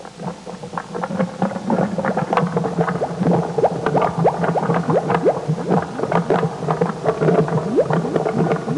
Clogged Sewer Sound Effect
Download a high-quality clogged sewer sound effect.
clogged-sewer.mp3